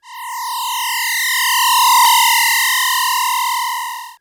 Teleport4.wav